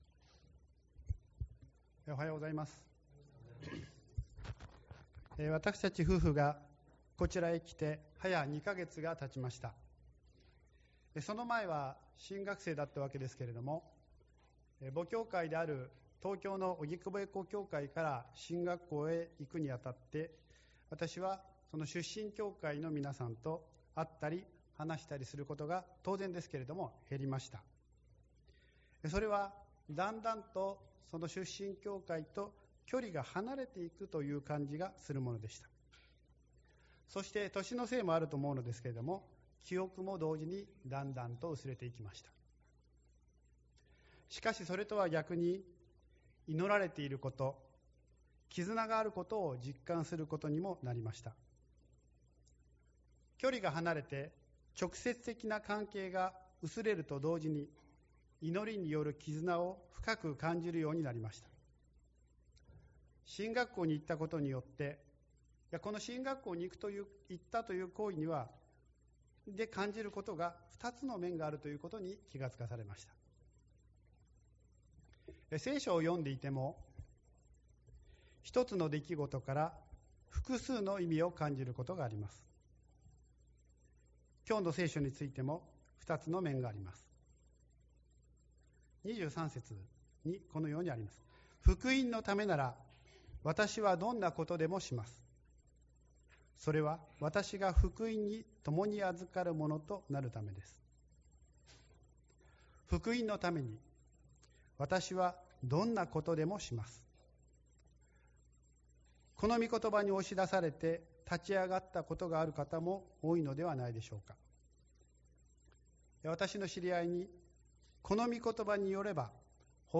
宣教題 「祝福の根源」 宣 教